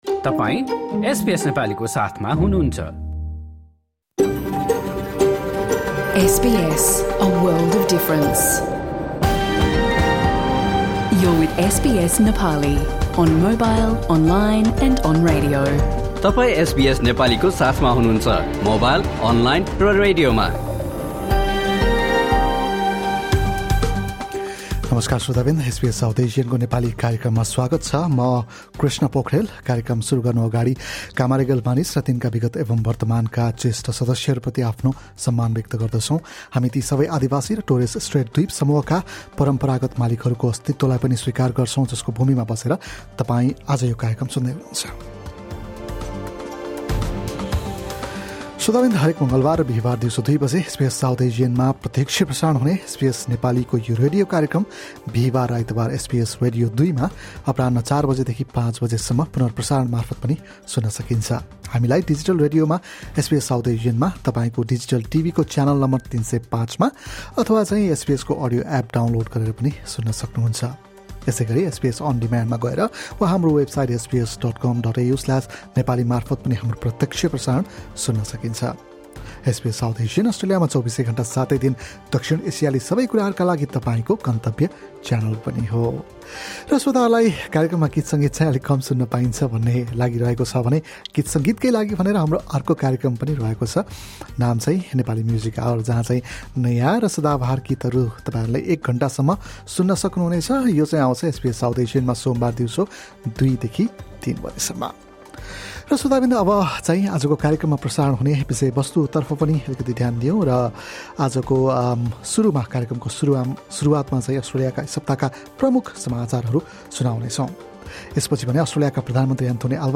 हाम्रो पछिल्लो रेडियो कार्यक्रम सुन्नुभयो? मङ्गलवार, २ डिसेम्बर सन् २०२५ दिउँसो २ बजे एसबीएस साउथ एसियनमा प्रत्यक्ष प्रसारण भएको एसबीएस नेपालीको कार्यक्रममा हामीले पछिल्लो एक हप्ताका अस्ट्रेलियन समाचार, प्रधानमन्त्री एन्थोनी अल्बानिजीको विवाह, मधुमेह रोगको लक्षण र उपचार, नेटिभ टाइटलको वास्तविक अर्थ, नेपाल समाचार लगायत विभिन्न विषयहरू जोडेका छौँ। हाम्रो रेडियो कार्यक्रम हरेक मङ्गलवार र बिहीवार दिउँसो दुई बजे SBS South Asian मा प्रत्यक्ष प्रसारण हुन्छ।